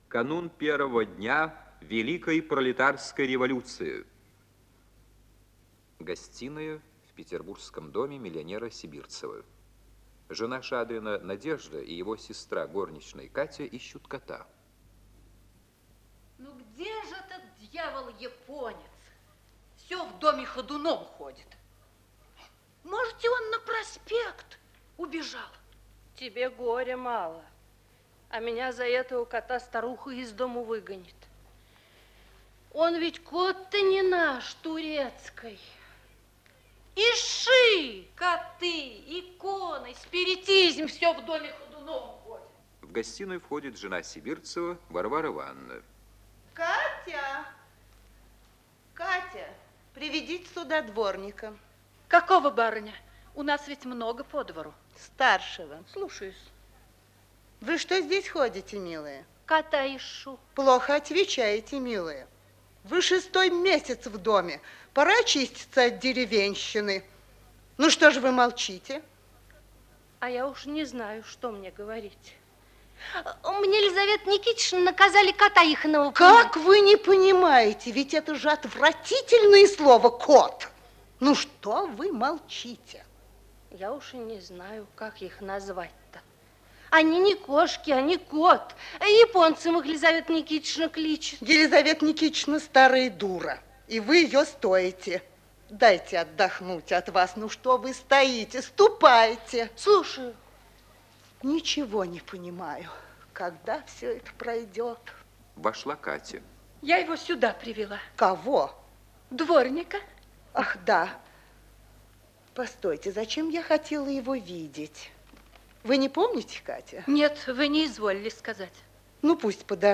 Исполнитель: Артисты театра им. Вахтангова